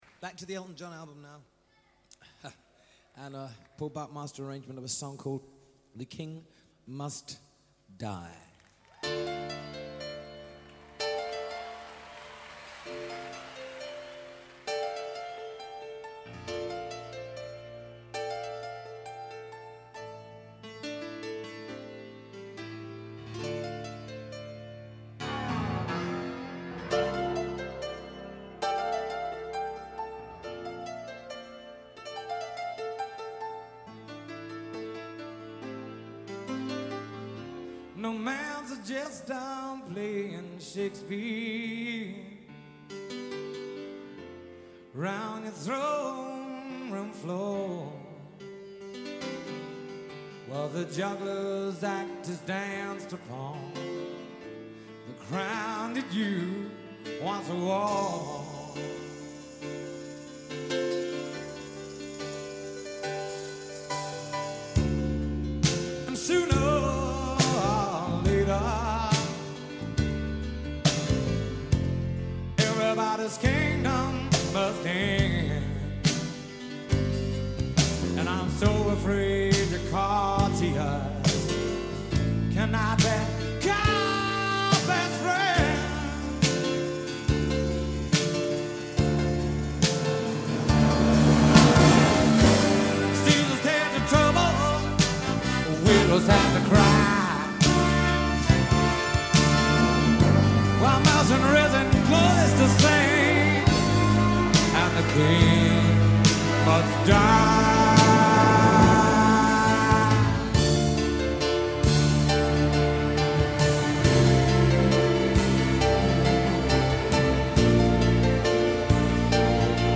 I’ve always had a soft spot for this live album.